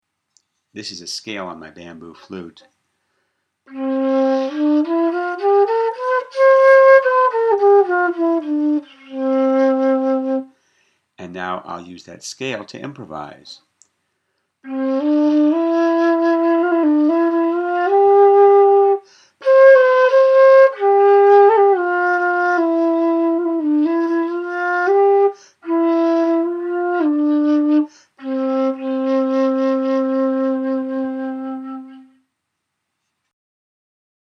Scale and Improvisation- Bamboo Flute
Scale-and-Improvisation-Bamboo-Flute.mp3